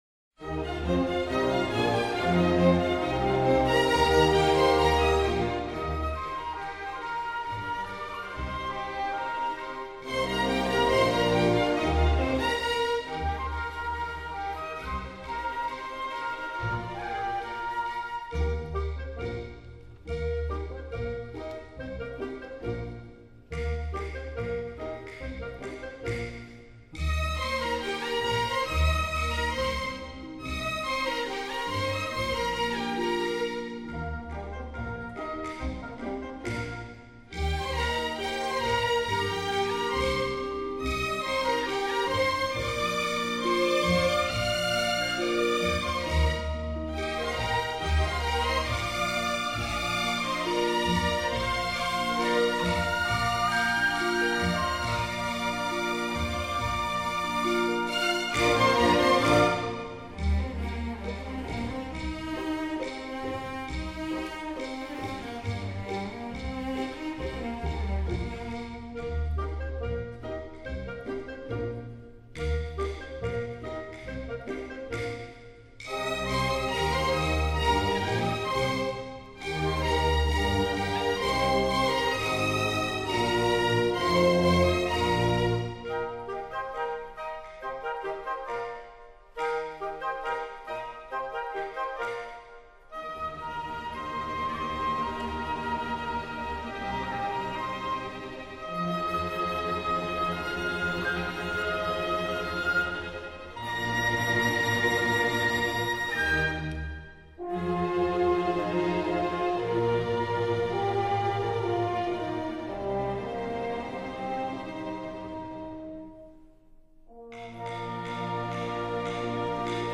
全部曲目充分结合了管弦乐器和民族乐器，使得音乐本身即更加立体感，又不失民族本色
音乐类型: 民乐
录音日期及地点：1992年6月·上海